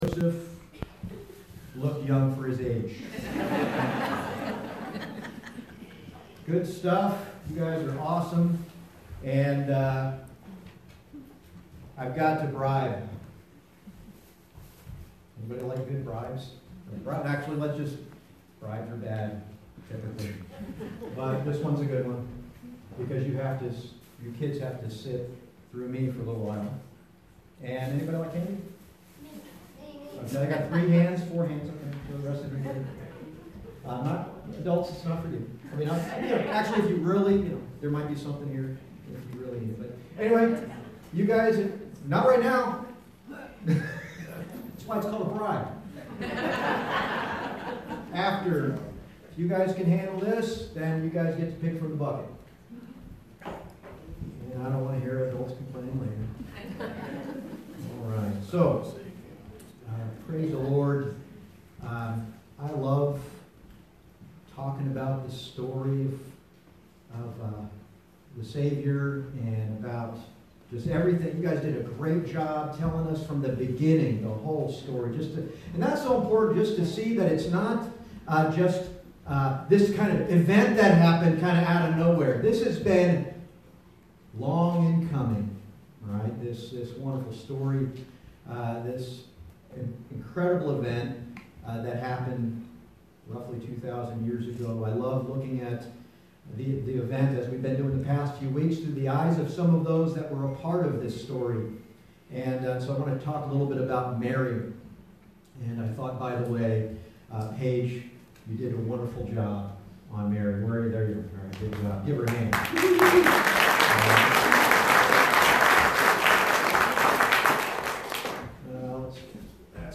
Luke 1:26-38 Service Type: Sunday Morning « Simeon